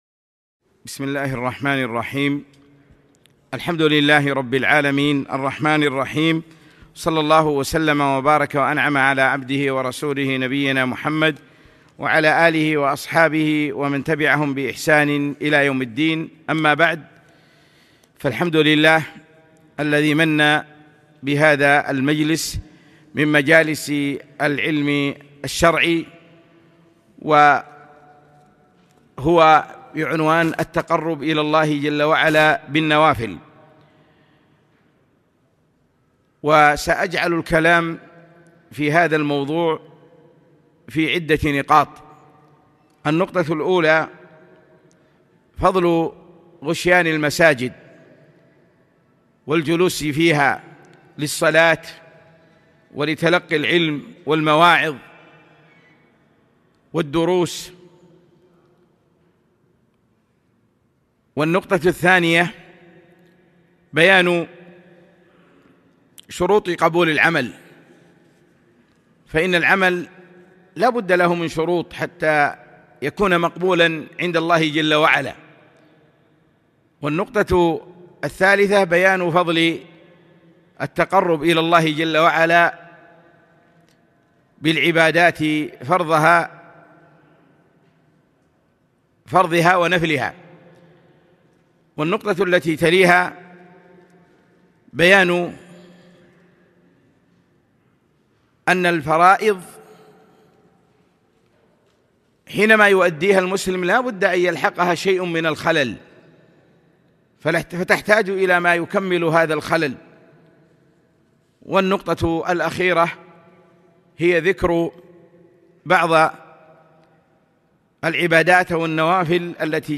محاضرة - التقرب إلى الله بالنوافل